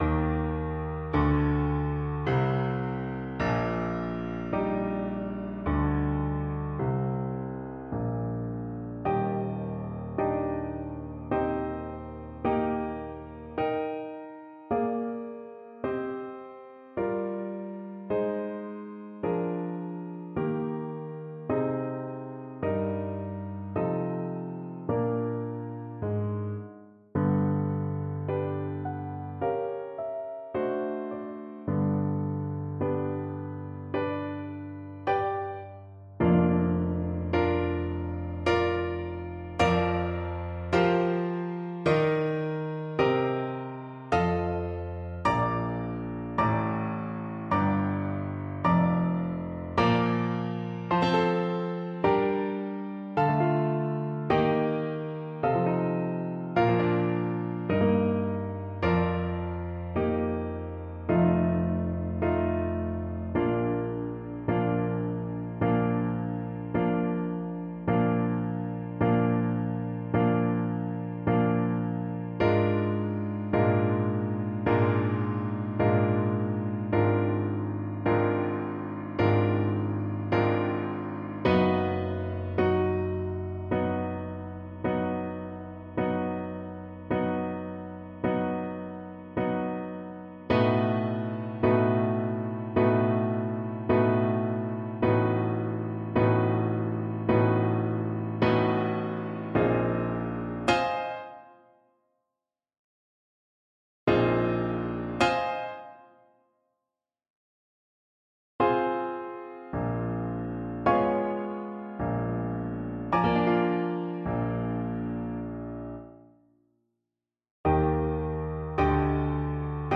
Classical Cécile Chaminade Concertino Op. 107 Trumpet version
Play (or use space bar on your keyboard) Pause Music Playalong - Piano Accompaniment Playalong Band Accompaniment not yet available transpose reset tempo print settings full screen
Trumpet
Moderato = 78
4/4 (View more 4/4 Music)
Eb major (Sounding Pitch) F major (Trumpet in Bb) (View more Eb major Music for Trumpet )
Classical (View more Classical Trumpet Music)